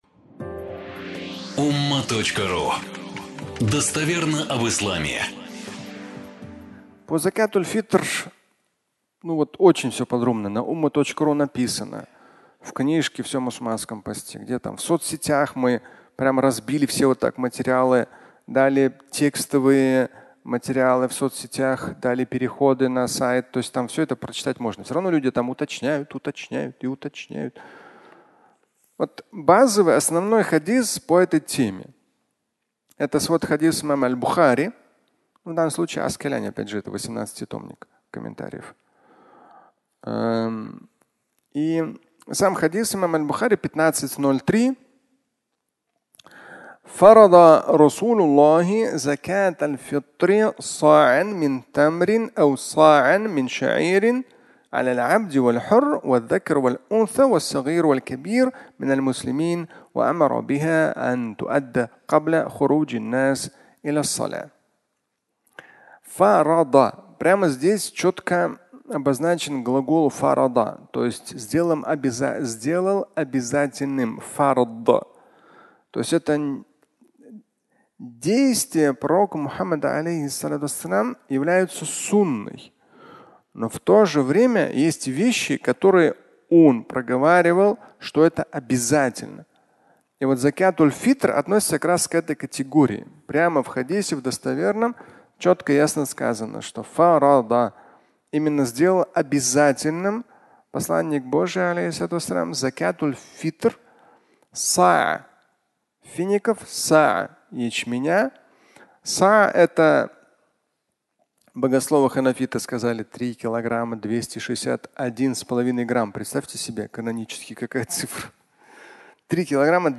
Закятуль-фитр (аудиолекция)